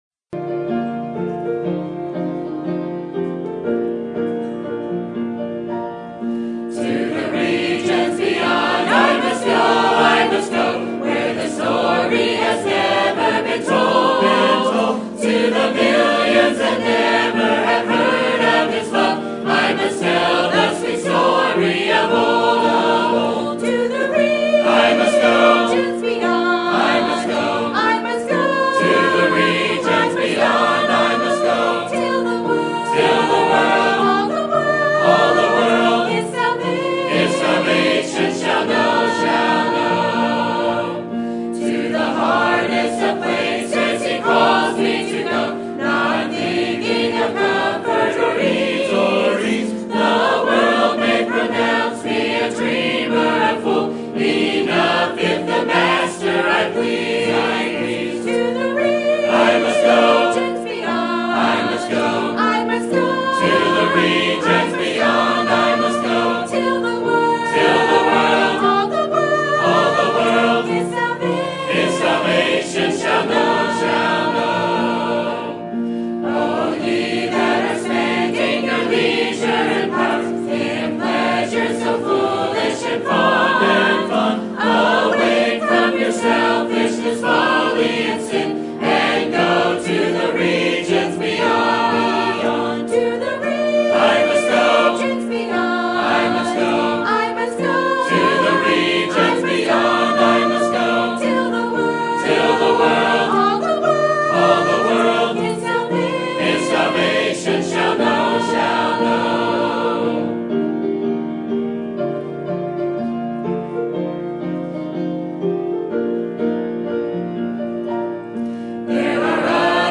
Isaiah 6:1-8 Read... Sermon Topic: Missions Conference Sermon Type: Special Sermon Audio: Sermon download: Download (18.61 MB) Sermon Tags: Isaiah Moses Call Missions